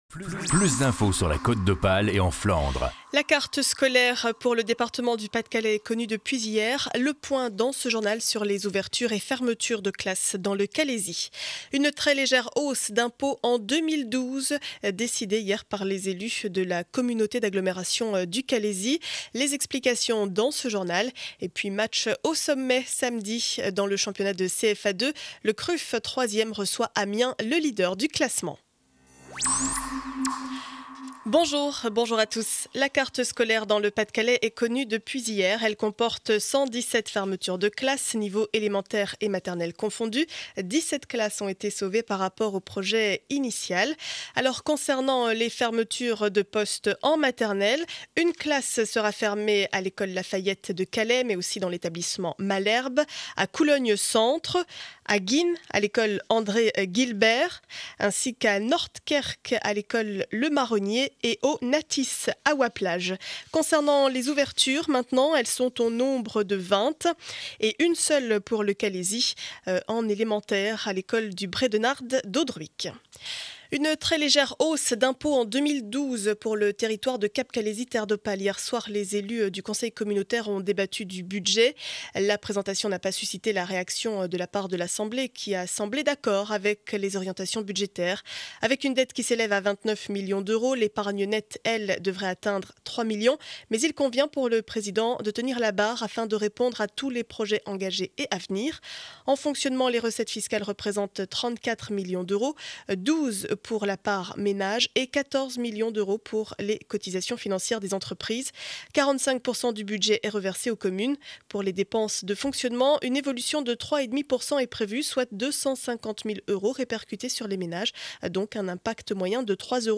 Journal du vendredi 24 février 2012 7 heures 30 édition du Calaisis.